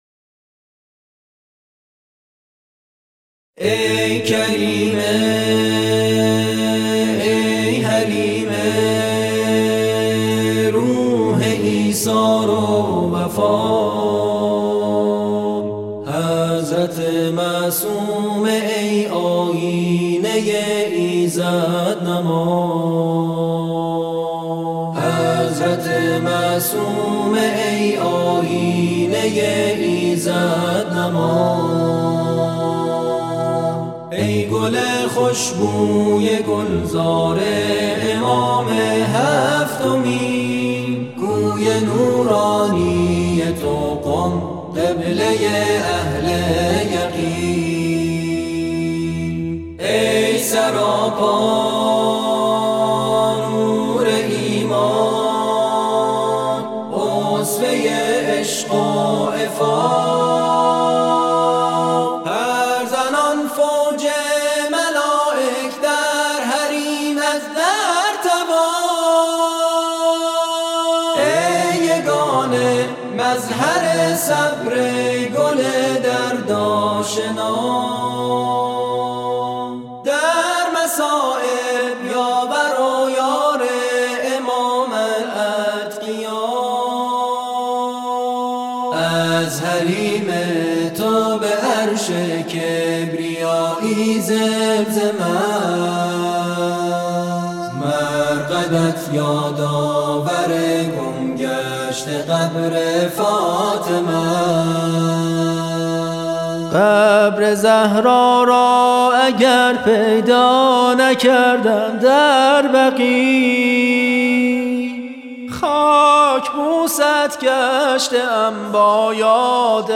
نغمات آئینی